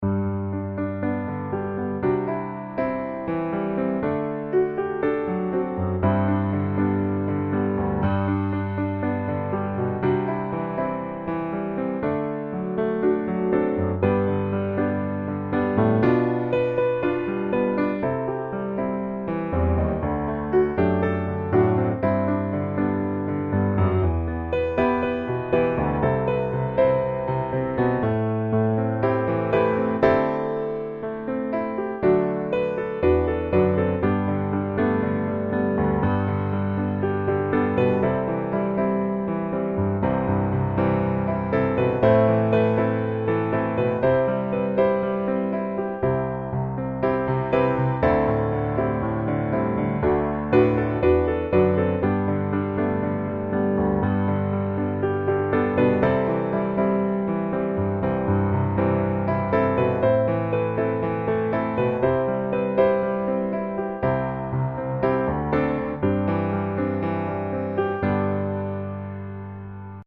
It is a very rhythmic and pleasant song.
G Major